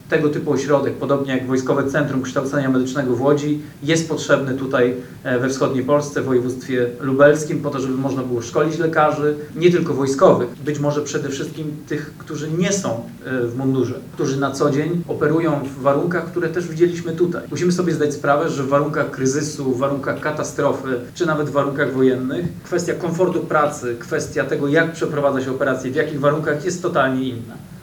Wiceminister obrony narodowej Cezary Tomczyk mówił w Lublinie, że priorytetowym działaniem jest powstanie w ciągu następnego roku przy Wojskowym Szpitalu Klinicznym Centrum Medycyny Wojskowej i Katastrof. Nowy ośrodek będzie szkolić lekarzy, pielęgniarki i ratowników medycznych z udzielania pomocy w trudnych warunkach.